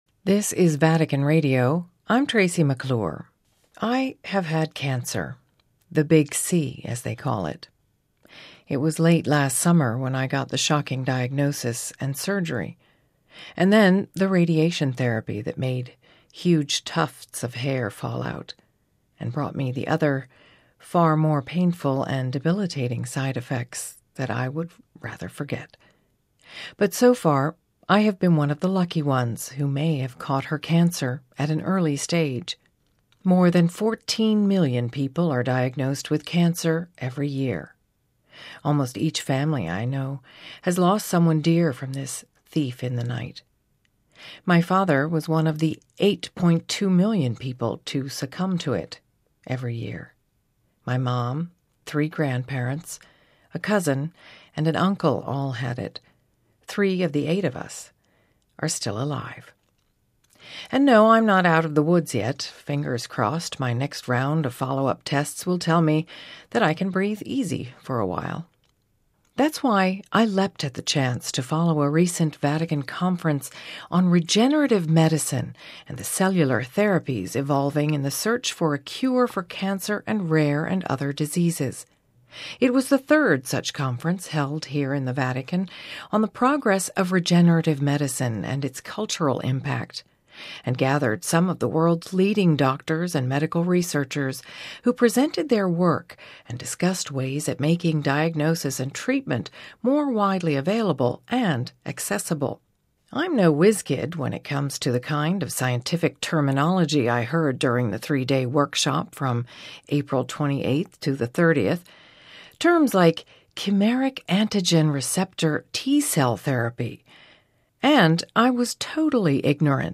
He explained to me how the vaccines work: